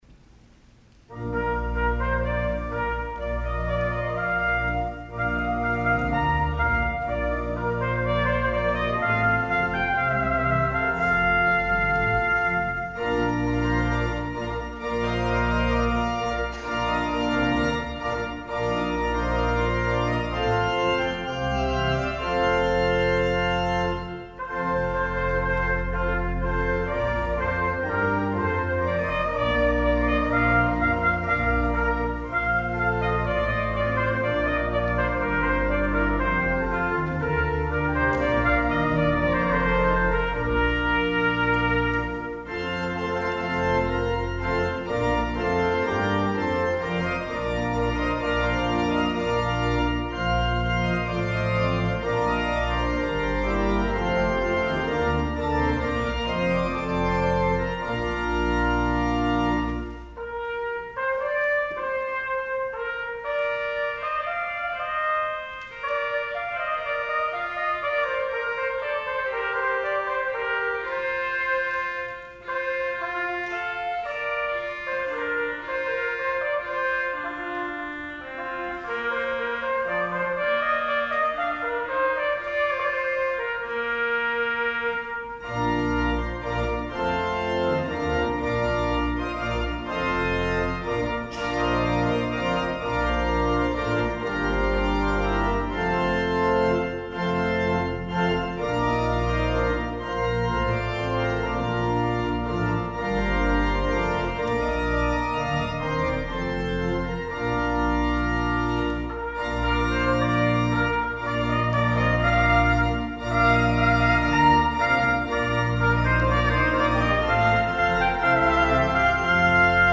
Selection:  Allegro (Concerto for Trumpet and Organ, BWV 595), Johann Sebastian Bach, 1685-1750
trumpet
organ